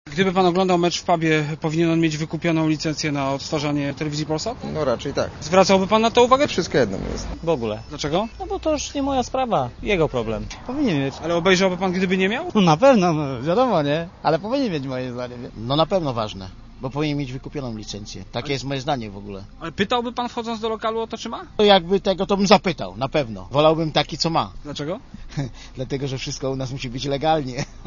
Sprawdziliśmy natomiast co o legalności publicznego odtwarzania meczów sądzą ci, dla których cała ta maszyna powinna działać, czyli klienci.